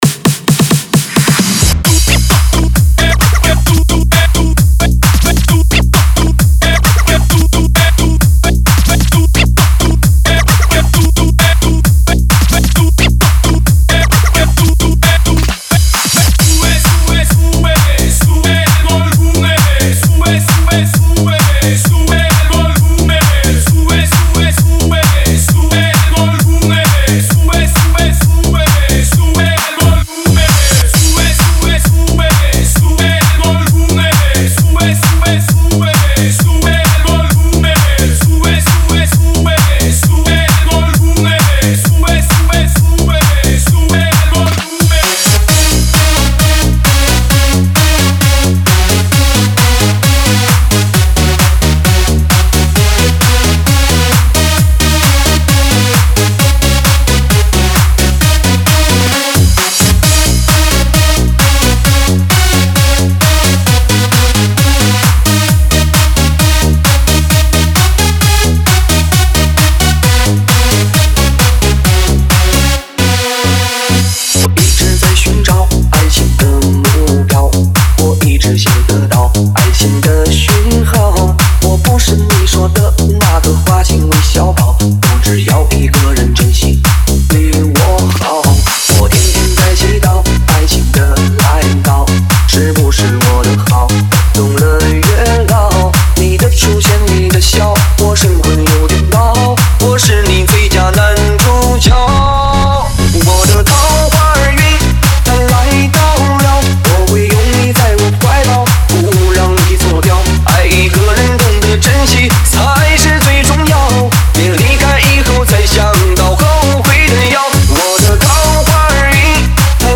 5天前 DJ音乐工程 · 沈阳风 2 推广